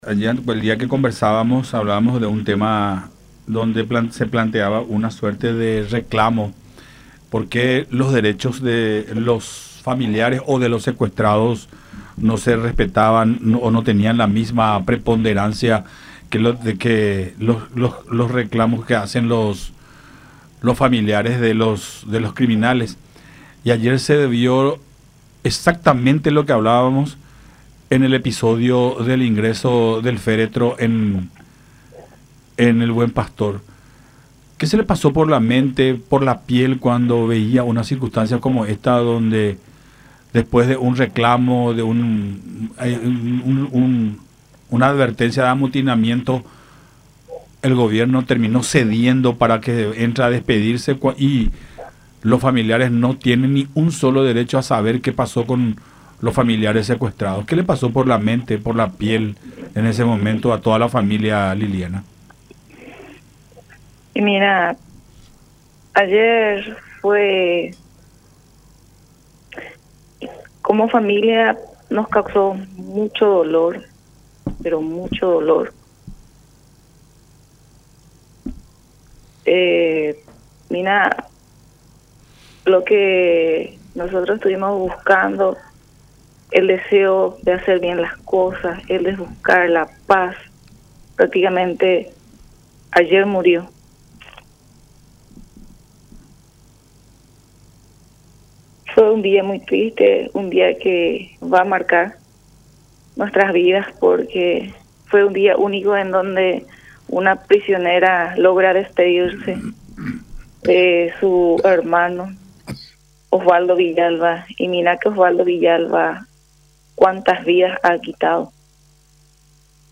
en contacto con Nuestra Mañana a través de Unión TV y radio La Unión